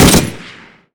sol_reklam_link sag_reklam_link Warrock Oyun Dosyalar� Ana Sayfa > Sound > Weapons > M60 Dosya Ad� Boyutu Son D�zenleme ..
WR_Fire.wav